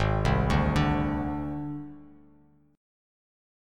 G#Mb5 chord